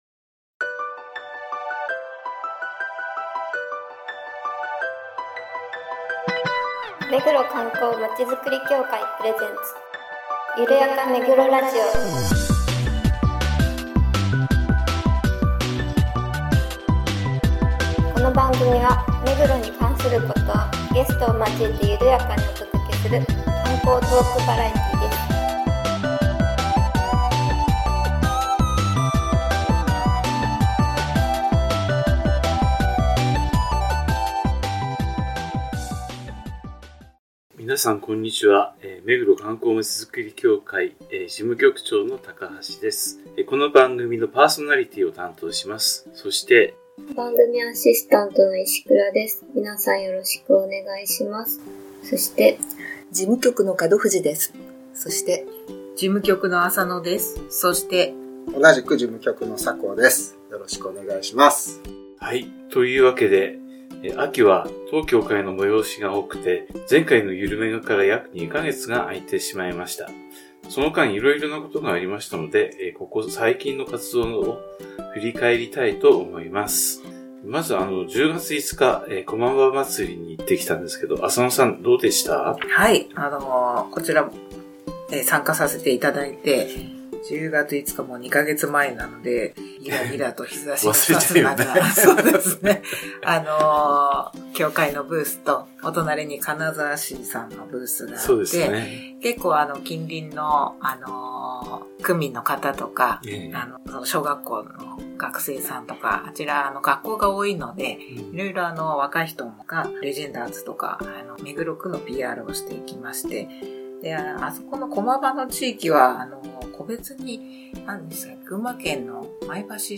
観光協会の面々が目黒に関する観光情報などゲストを交えて会話を展開するゆるやかトークバラエティ番組です。